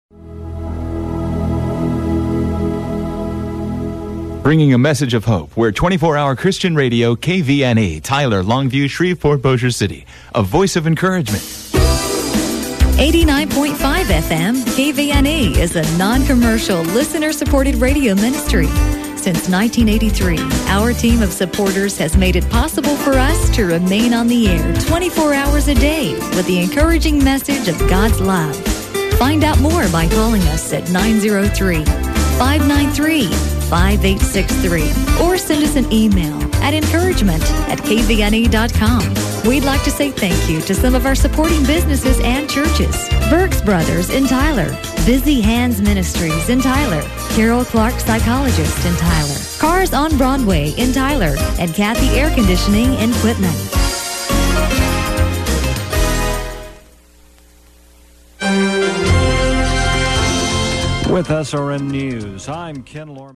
KVNE Top of the Hour Audio:
This FM Christian Contemporary radio station is licensed by the FCC to EDUCATIONAL RADIO FOUNDATION OF EAST TEXAS, INC. in Tyler, Texas, and serving Tyler-longview Area